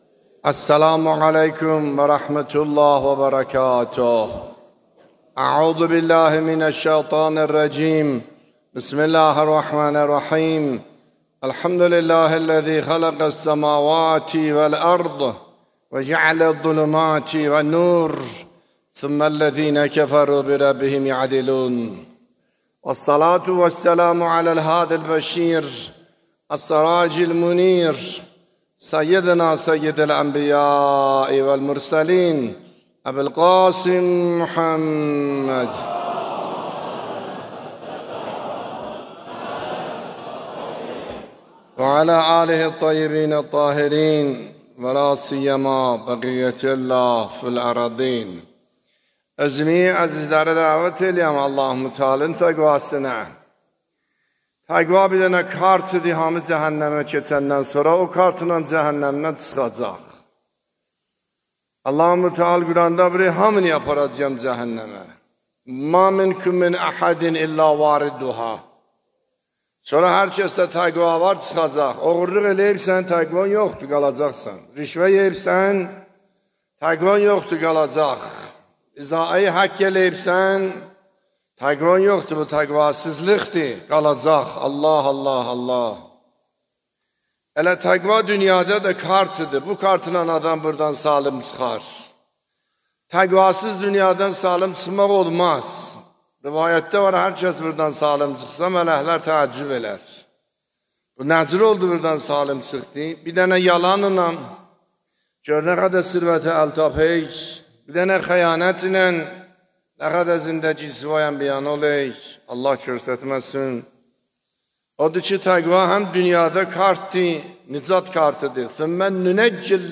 بیانات آیت الله سید حسن عاملی نماینده ولی فقیه و امام جمعه اردبیل در خطبه های نماز جمعه در 17 شهریور 1402